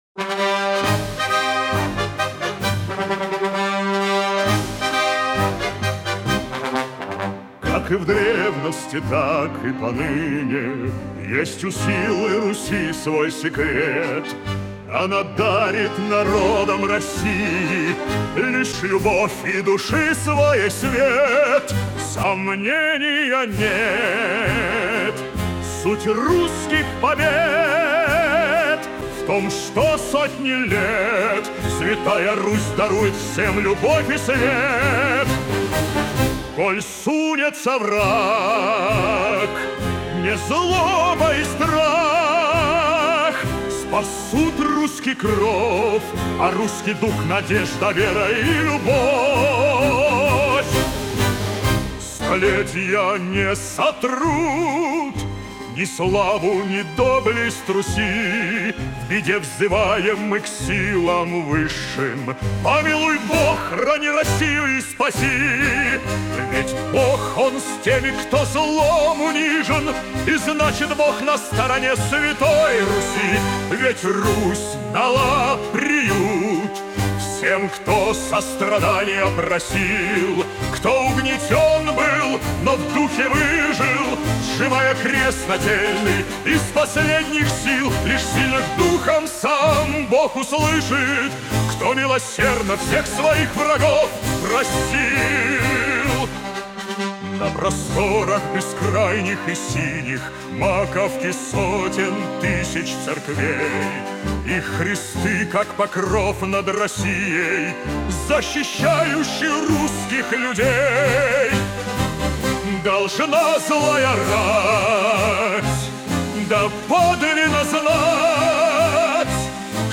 на мотив марша